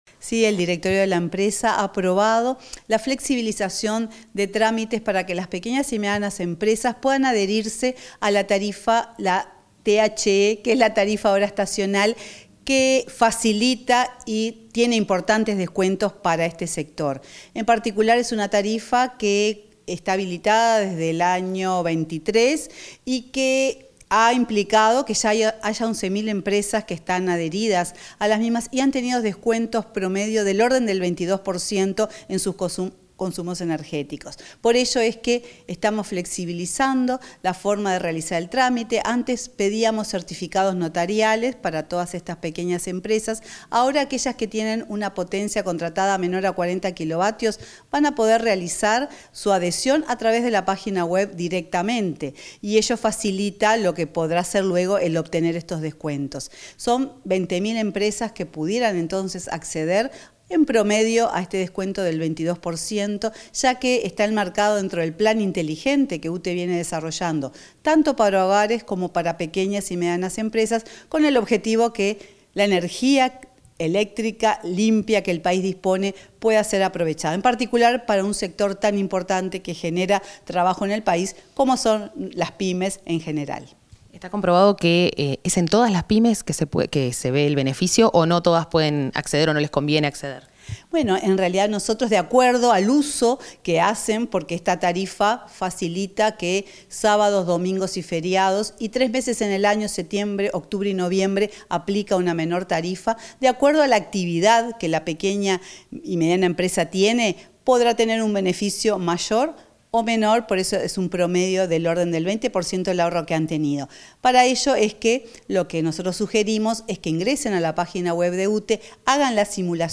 Entrevista a la presidenta de UTE, Silvia Emaldi
Así lo indicó la presidenta del ente, Silvia Emaldi, en entrevista con Comunicación Presidencial.